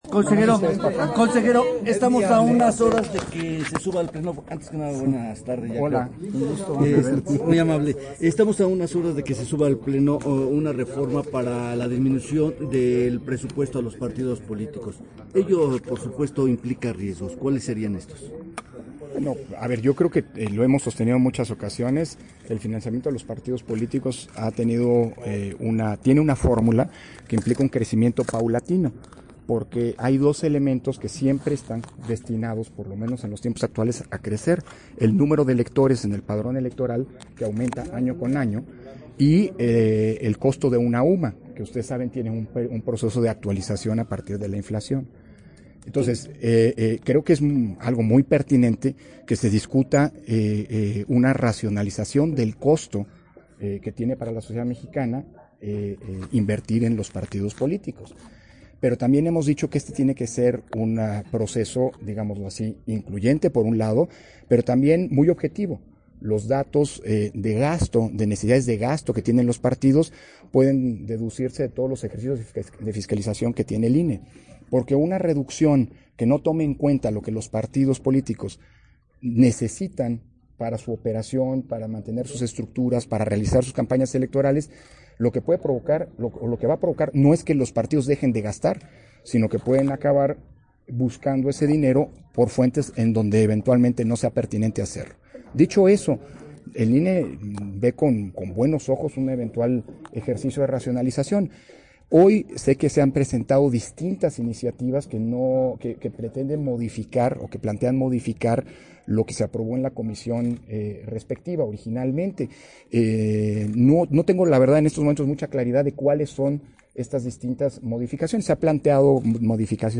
061119_AUDIO_ENTREVISTA CONSEJERO PDTE. CÓRDOVA-CÁMARA DIP. - Central Electoral